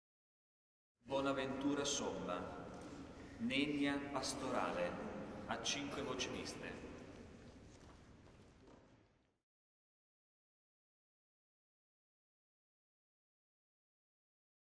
Elevazioni Musicali > 2000 > 2001
S. Alessandro in Colonna